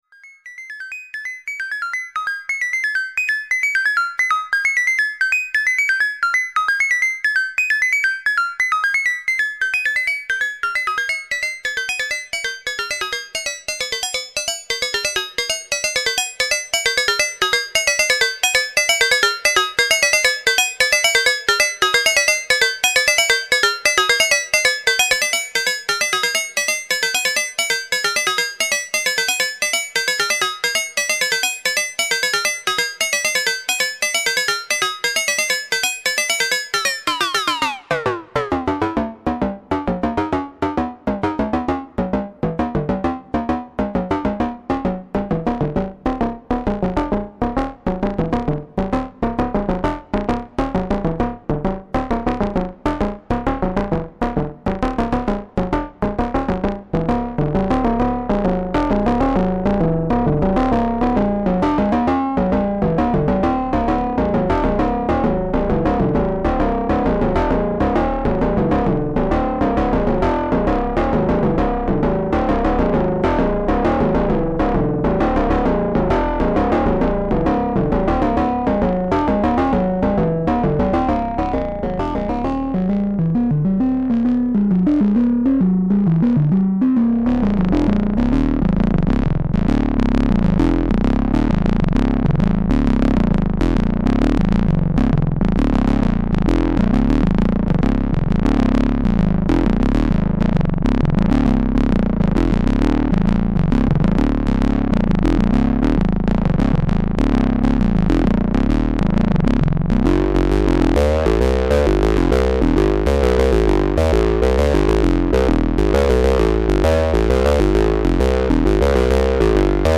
Basic patch using the DWS as a timbre modulator:
VCO1 triangle --> Diode Waveform Shaper --> Moog style filter --> VCA out --> digital reverb
VCOs are tuned to near perfect intervals relative to each other.
It starts with a high pitched triangle wave, then gain is increased on stages 1 and 4, bringing in the VCO2 modulation. The DWS limit switch is activated, which rounds the output waveform. Pitch is lowered, then VCO3 modulation is brought in, faded out (during ADSR tweaking), VCO4 modulation is brought in, faded out, then the combination is brought in. Pitch is increased and then various combinations of modulation are heard.